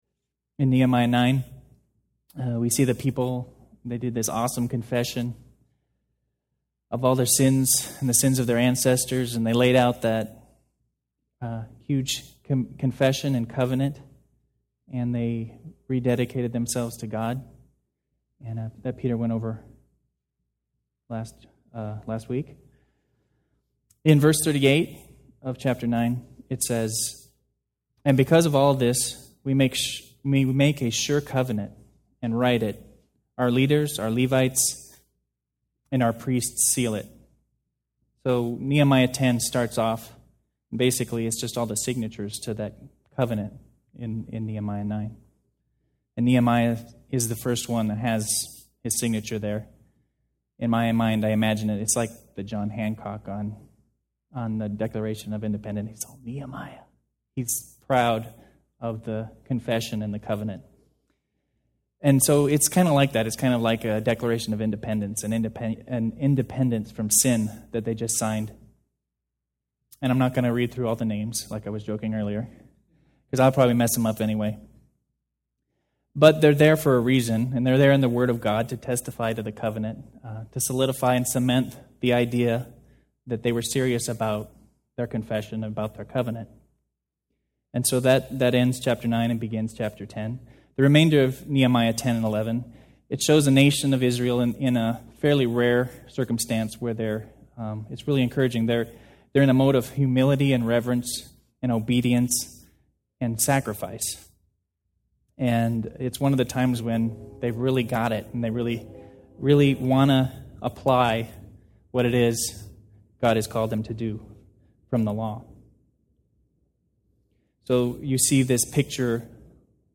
Service: Men's Bible Fellowship